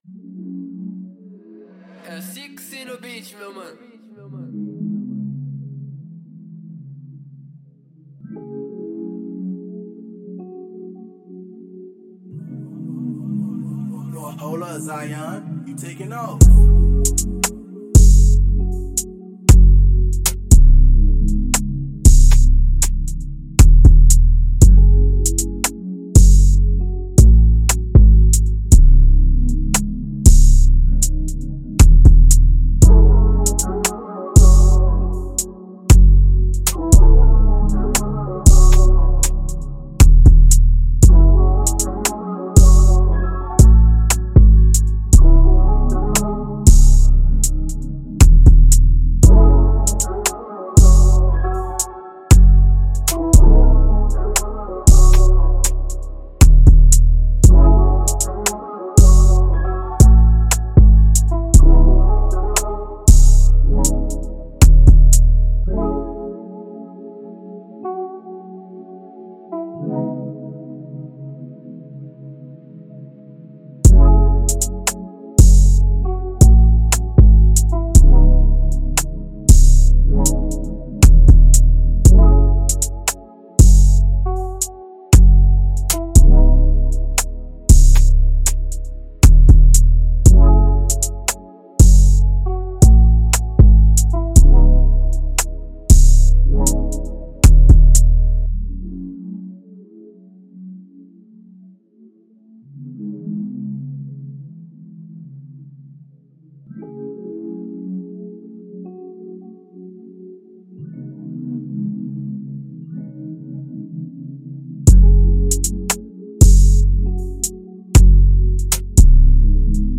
Mainstream
117 C# Minor